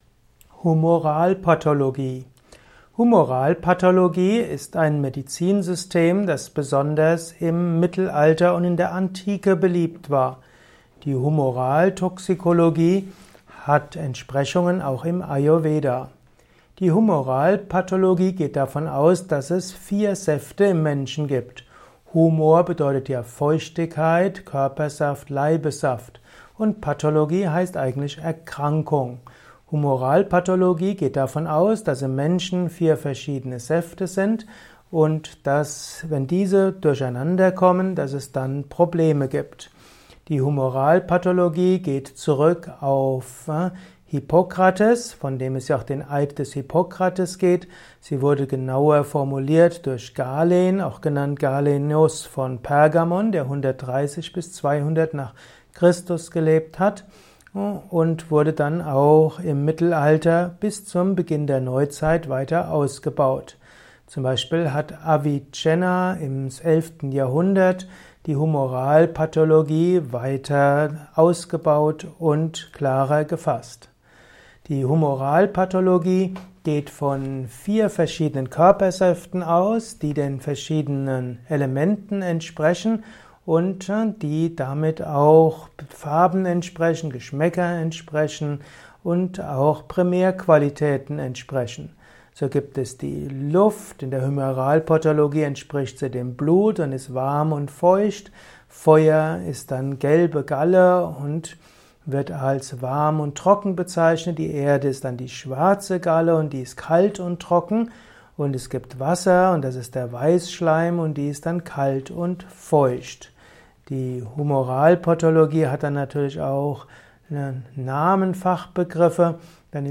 Vortragsaudio rund um das Thema Humoralpathologie. Erfahre einiges zum Thema Humoralpathologie in diesem kurzen Improvisations-Vortrag.